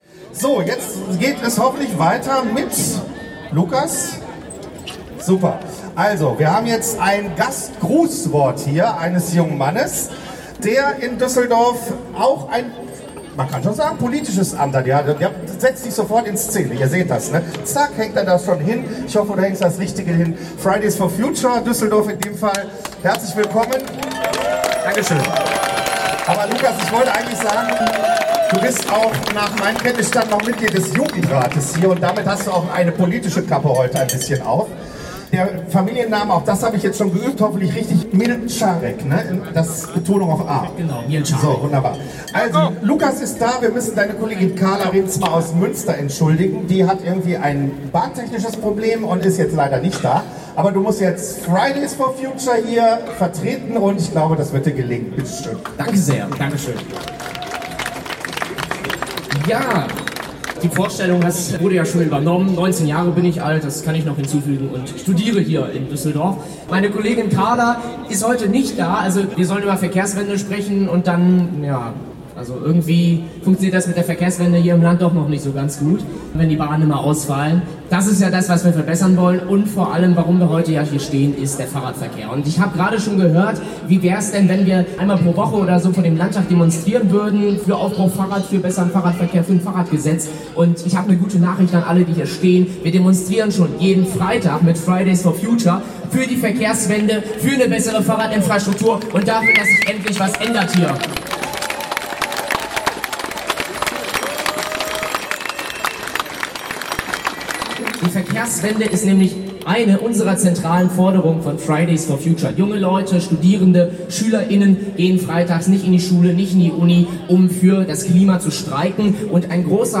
Kapitel 2: Kundgebung und Unterschriftenübergabe
Die Reden rund um das Anliegen der Volksinitiative „Aufbruch Fahrrad“